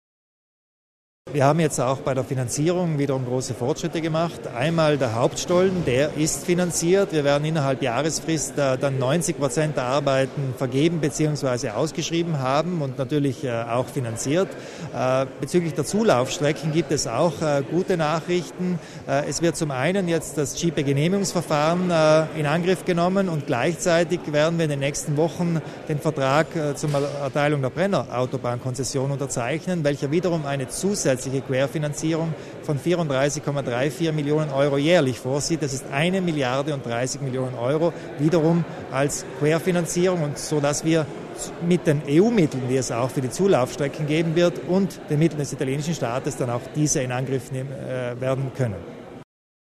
Landeshauptmann Kompatscher erläutert die Details zur Finanzierung des BBT
Beim heutigen Treffen (16. September) im Palais Widmann konnte Landeshauptmann Arno Kompatscher berichten, dass sämtliche Finanzmittel für den Bau des Hauptstollens bereits vorhanden seien. 30 Prozent der Bauarbeiten am BBT sind bereits vertraglich gebunden, innerhalb eines Jahres sollen insgesamt 90 Prozent der gesamten Arbeiten ausgeschrieben sein.